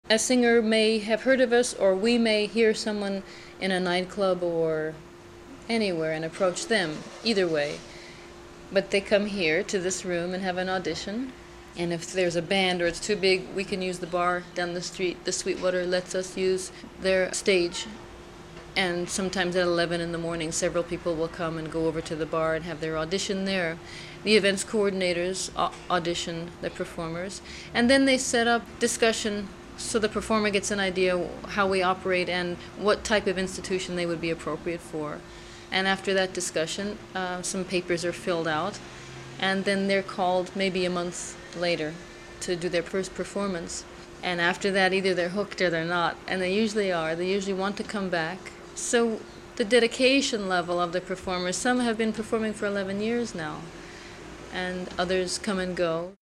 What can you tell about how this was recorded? for German public radio